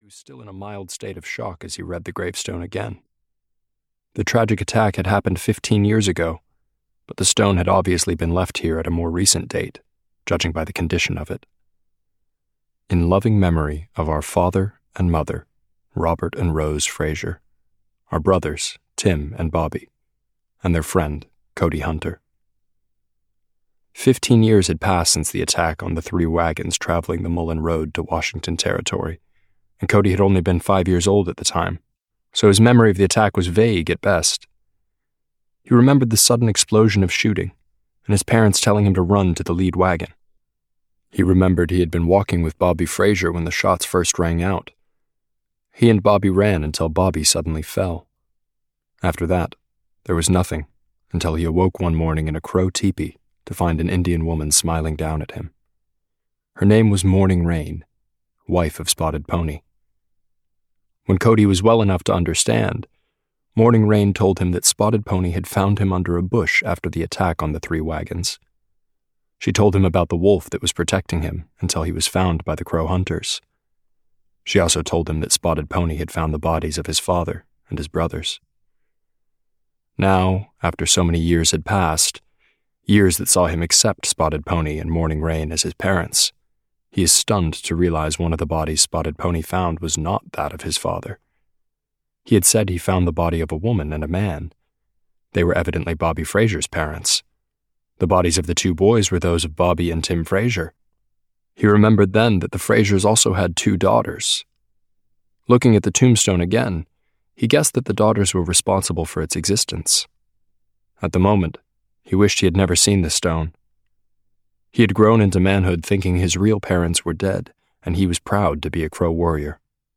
The First Day of Eternity (EN) audiokniha
Ukázka z knihy